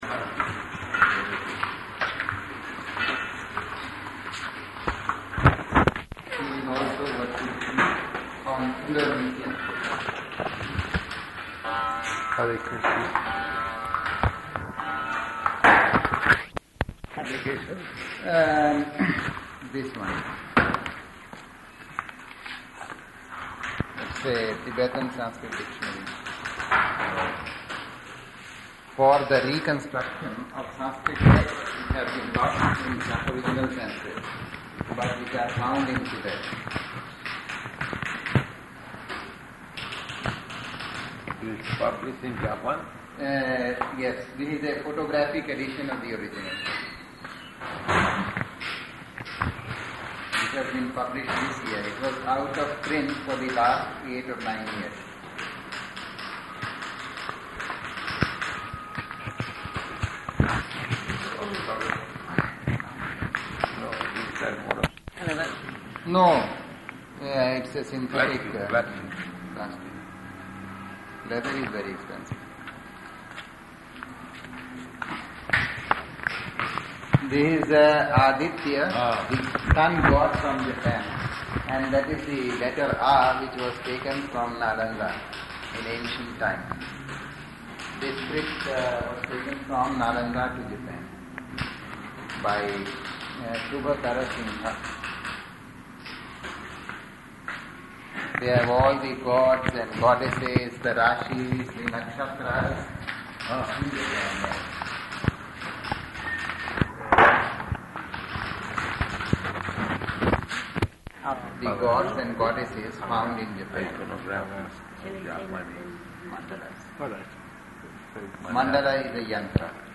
Type: Conversation
Location: Delhi